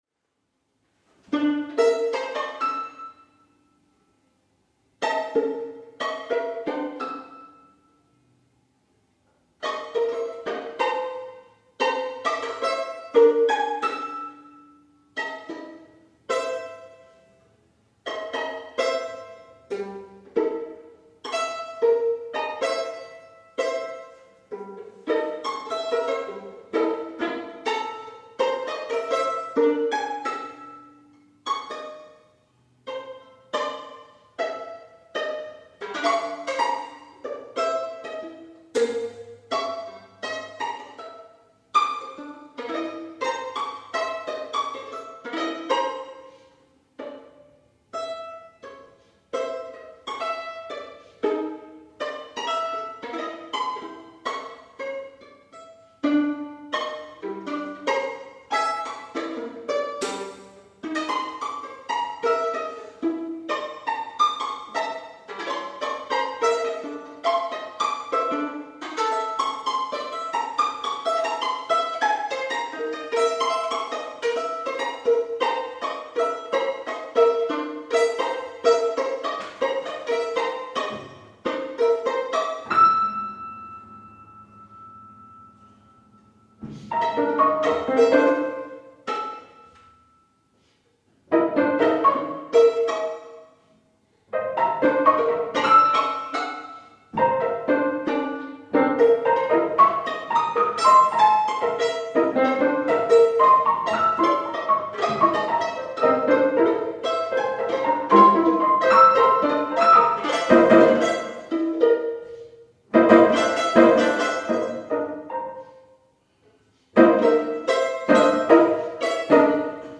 Live performance-27th April 2014 Kettle’s Yard, Cambridge